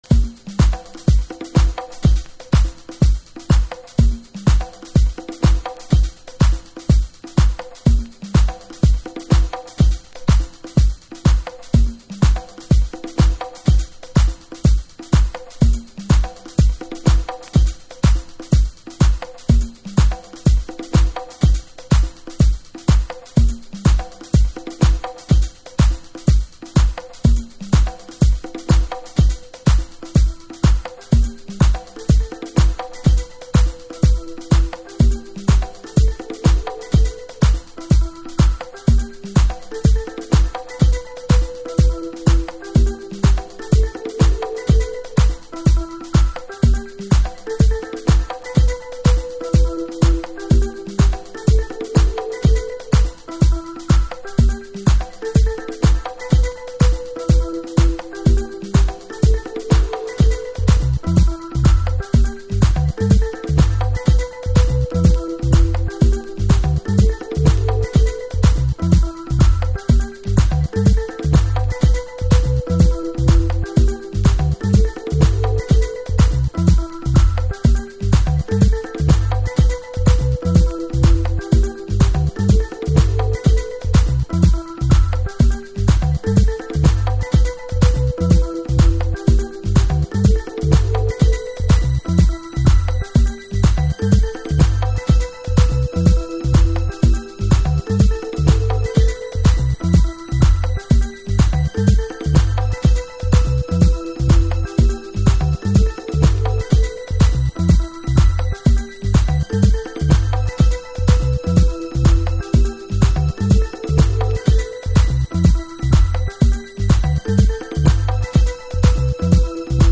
House / Techno
シンプルかつファンクショナルな2TRAX。